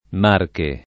[ m # rke ]